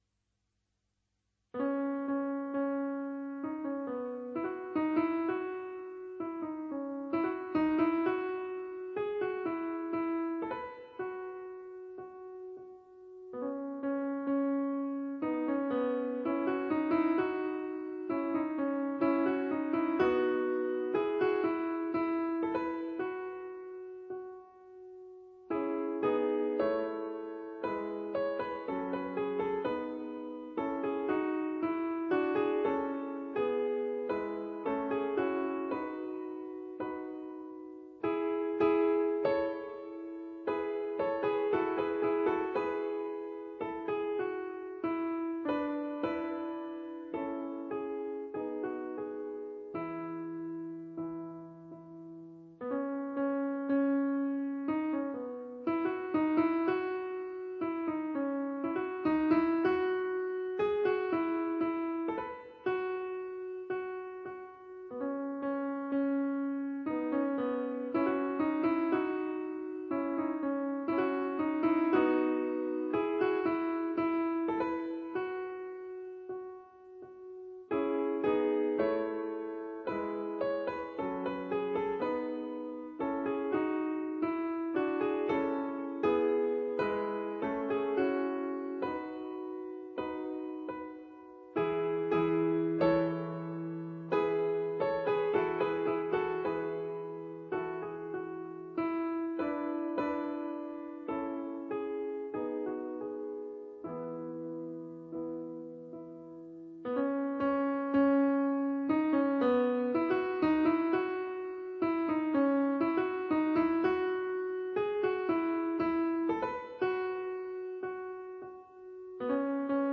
ADATTAMENTI PER PIANO